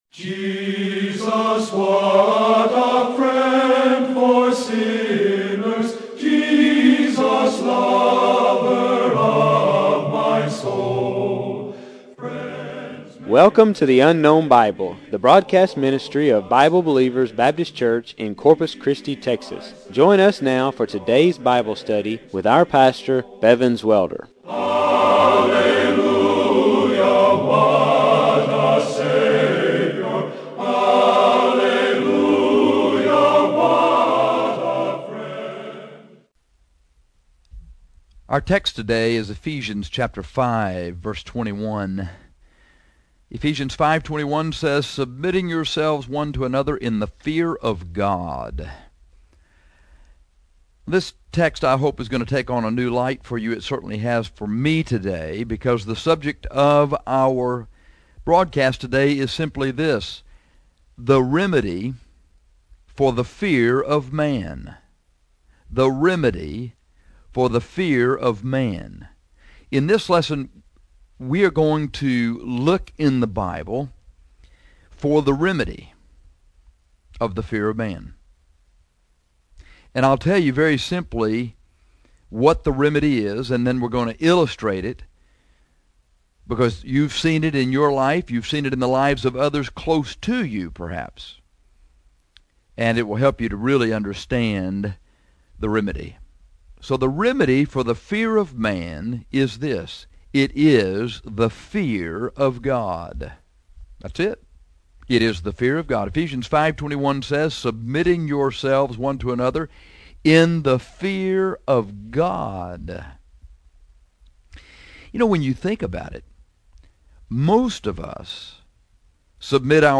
In this lesson we are going to look in the Bible for a remedy for the fear of man.